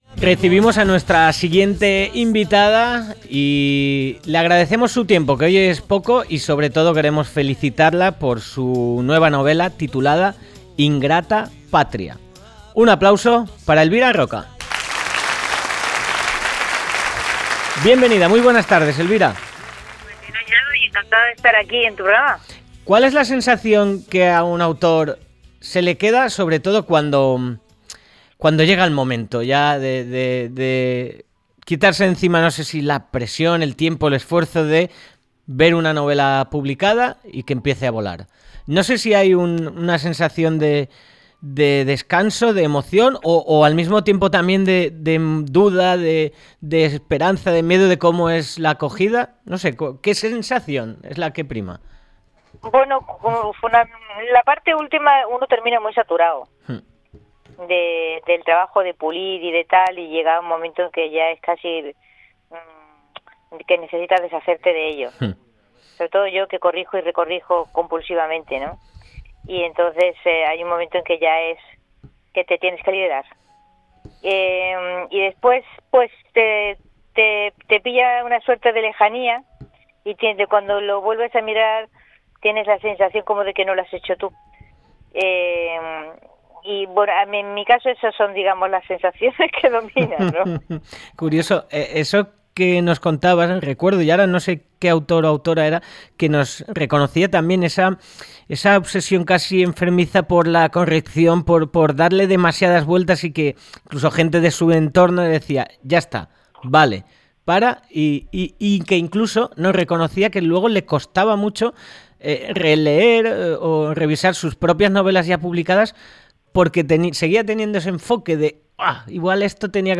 Entrevista a Elvira Roca Barea Meliá Casa de Las Artes